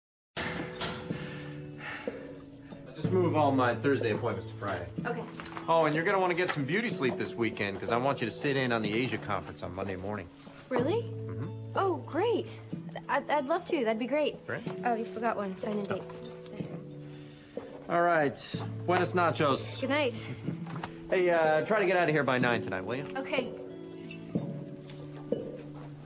在线英语听力室影视剧中的职场美语 第46期:指派工作的听力文件下载,《影视中的职场美语》收录了工作沟通，办公室生活，商务贸易等方面的情景对话。